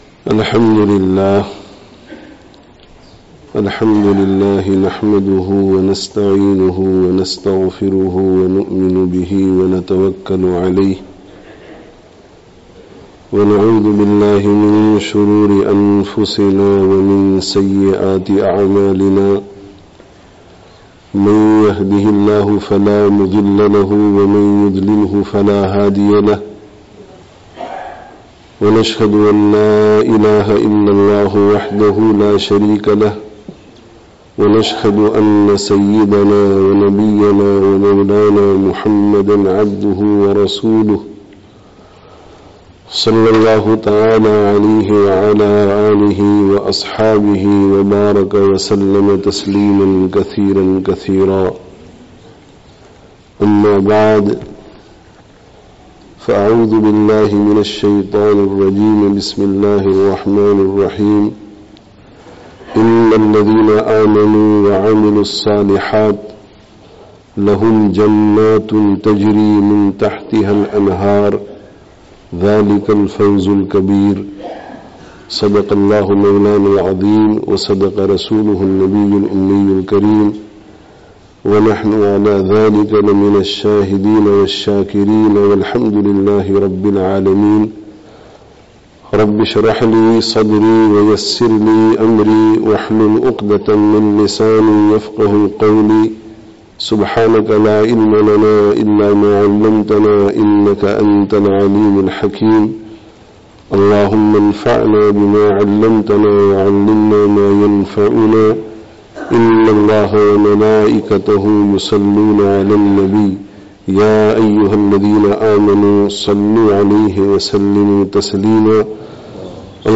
Īmān and 'Amale Sālih: Guarantee to All Success (Jame Masjid, Markham, Canada 09/11/18)